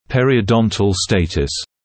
[ˌperɪəu’dɔntl ‘steɪtəs][ˌпэриоу’донтл ‘стэйтэс]пародонтологический статус; текущее состояние пародонта